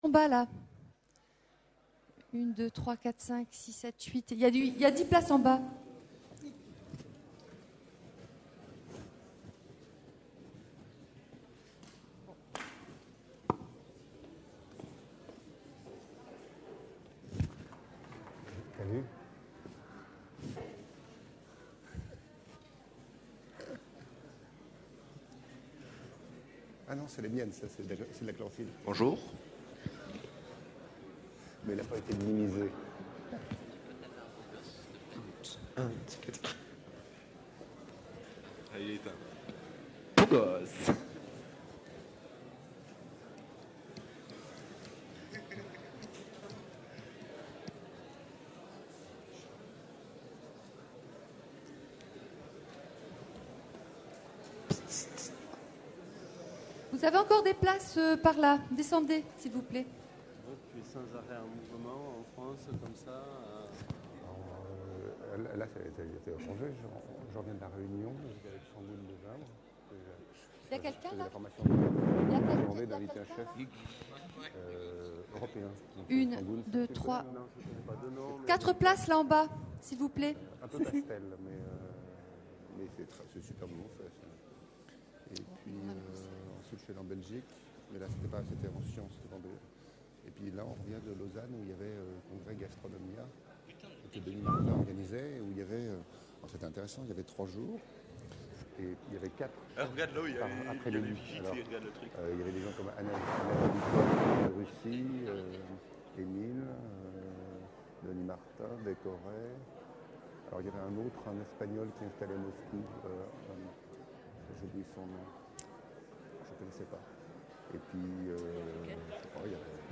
Une conférence de l'UTLS au Lycée par Hervé THIS, chimiste INRA et Michel Sarran, grand chef en partenariat avec Agrobioscience